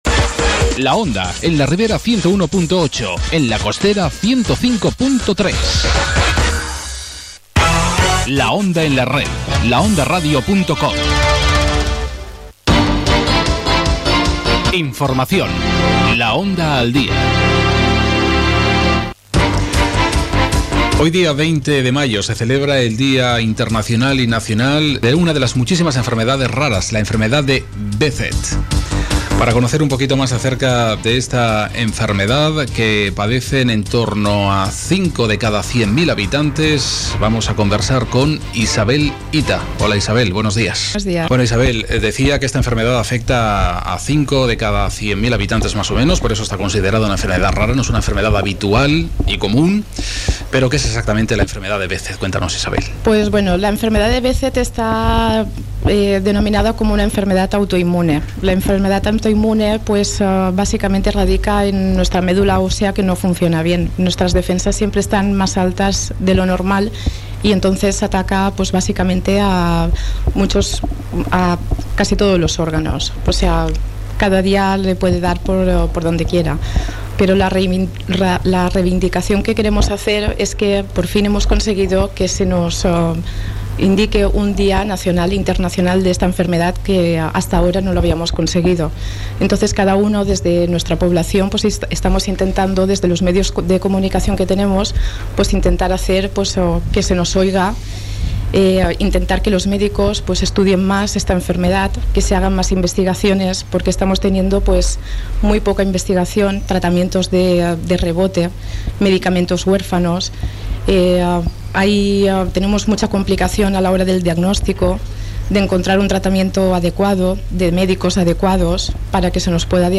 >Podéis escuchar la entrevista radiofónica realizada a una de las persona con esta enfermedad